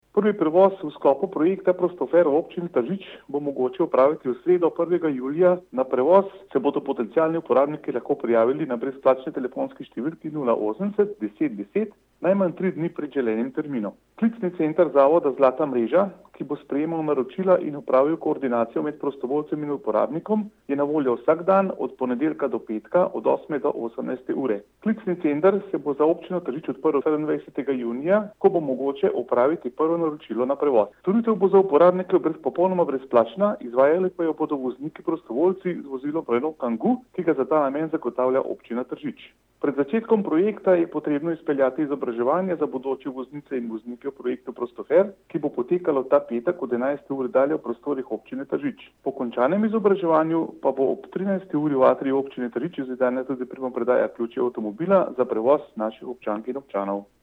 izjava_dusanbodlajpodzupanobcinetrzic.mp3 (1,4MB)